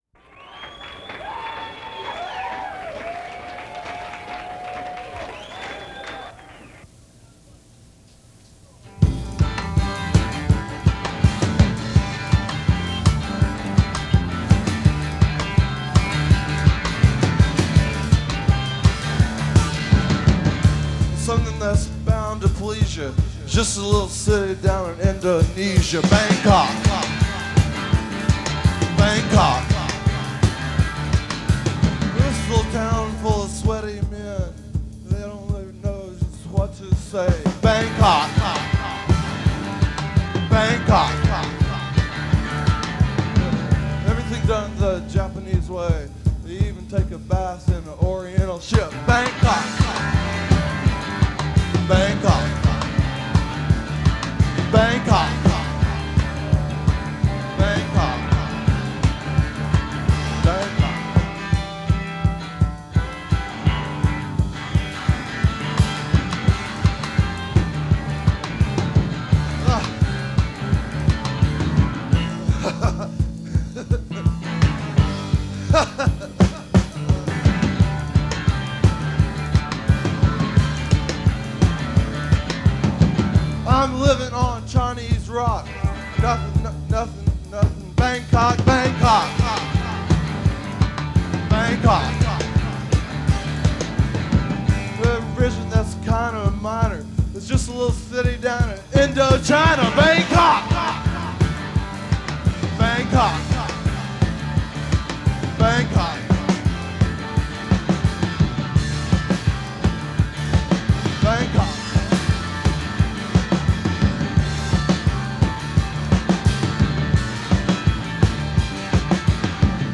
Live at the 40-Watt Club, Athens Georgia
Band soundboard
vocals